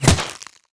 bullet_impact_ice_08.wav